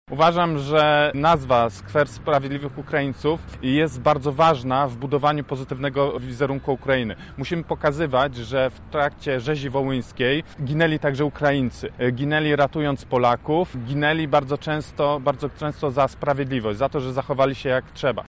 – dodaje Jakub Kulesza.